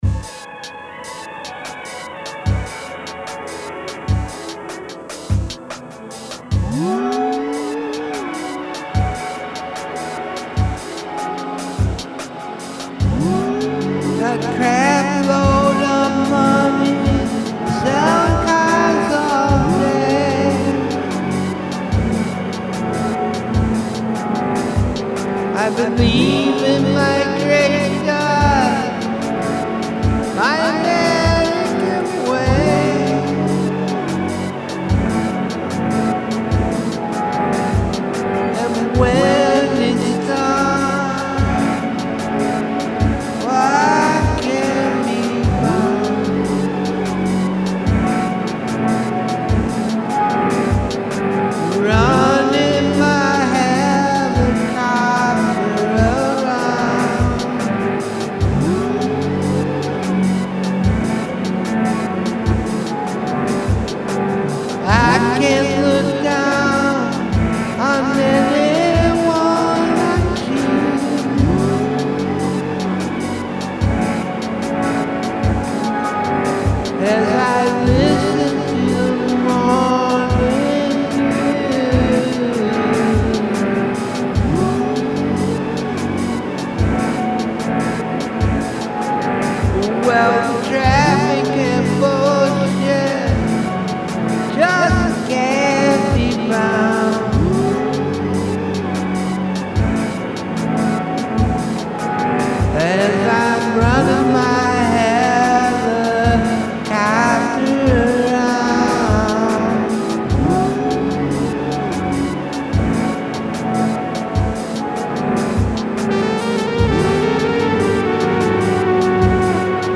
rock and/or roll